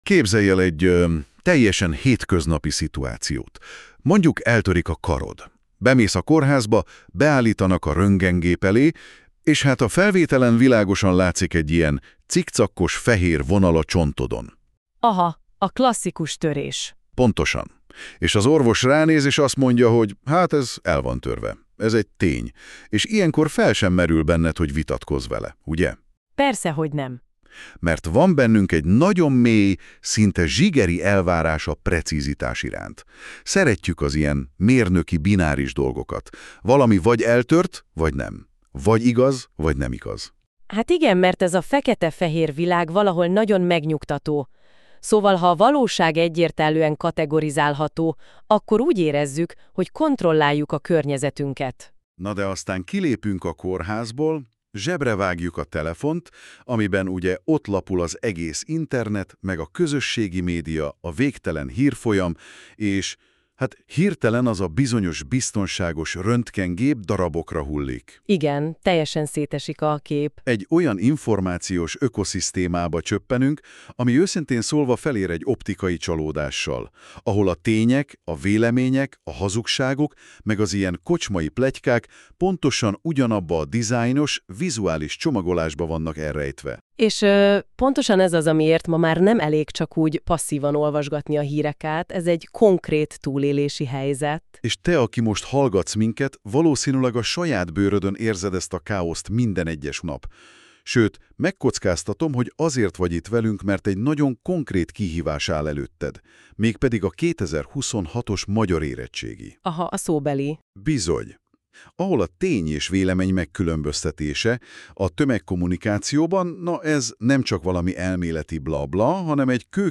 Két házigazda beszélgetése magyarul.